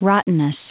Prononciation du mot rottenness en anglais (fichier audio)
Prononciation du mot : rottenness